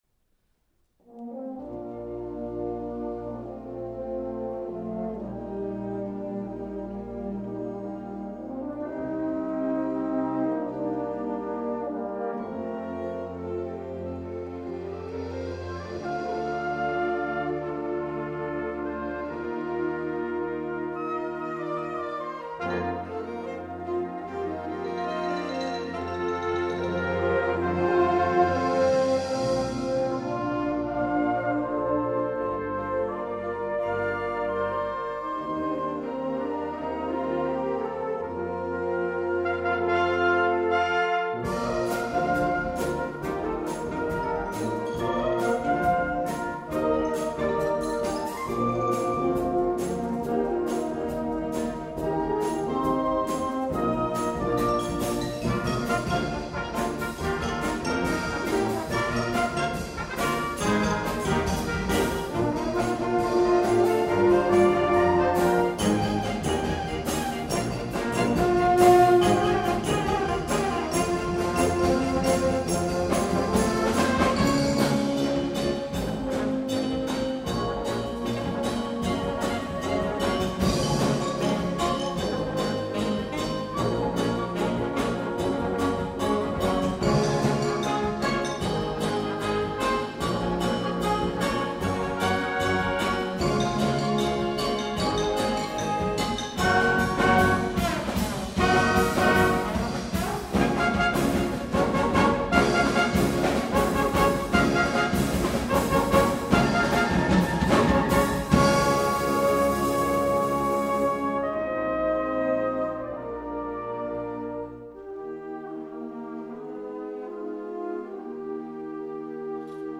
Catégorie Harmonie/Fanfare/Brass-band
Sous-catégorie Musique de concert
Instrumentation Ha (orchestre d'harmonie)
Un château féerique enchanteur se dévoile en musique.
Un pur moment de romantisme et de frissons !